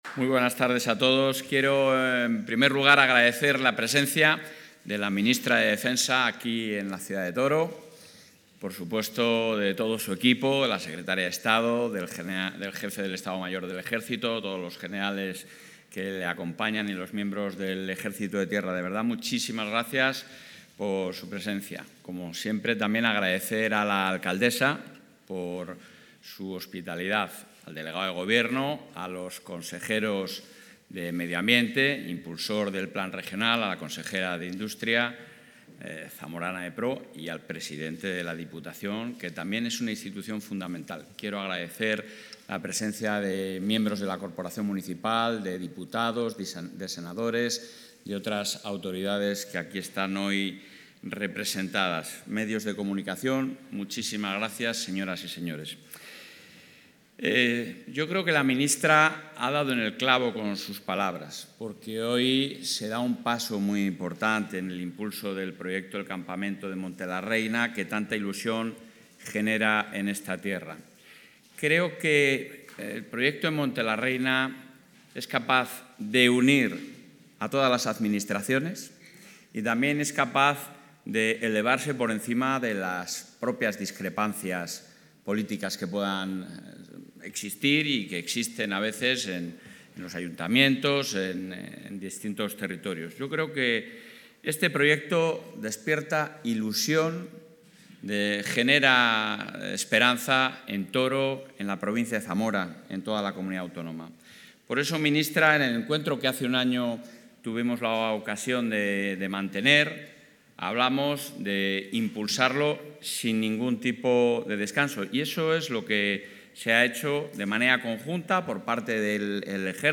El presidente de la Junta de Castilla y León, Alfonso Fernández Mañueco, ha presentado hoy, en el municipio zamorano de Toro, el...
Intervención del presidente de la Junta.